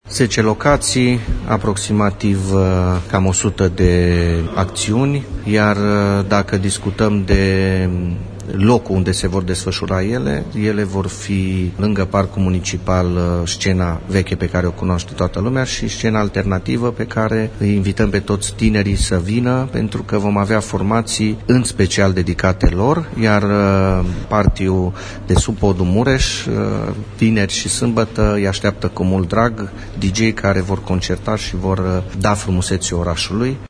Programul celei de a 21-a ediții a evenimentului a fost prezentat astăzi de reprezentanții municipalității într-o conferință de presă.